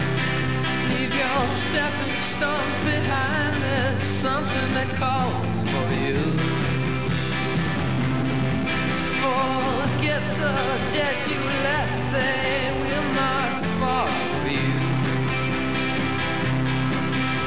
A very clear section of a sailor's song